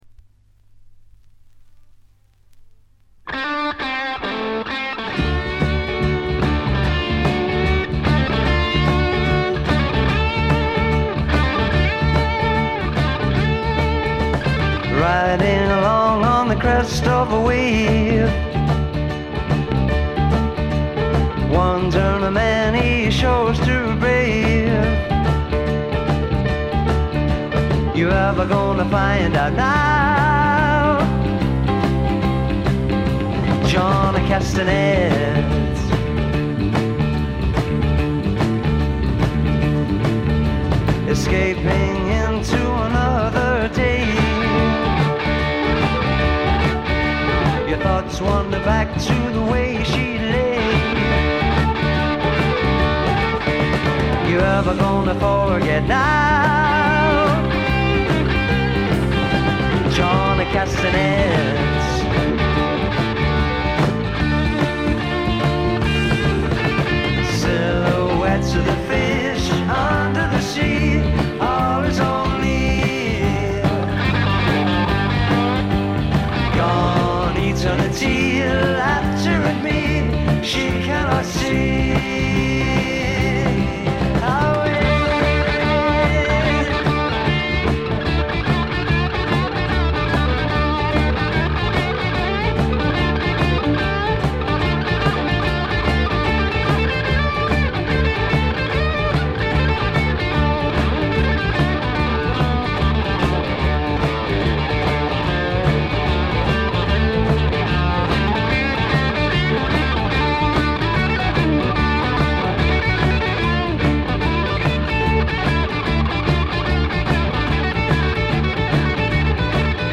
ごくわずかなノイズ感のみ。
内容は1971年という時代背景にあって典型的かつ最良の英国流フォーク・ロックです。
試聴曲は現品からの取り込み音源です。